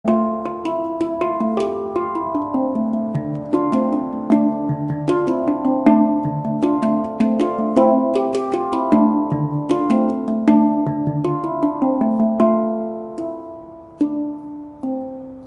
Метки: спокойные,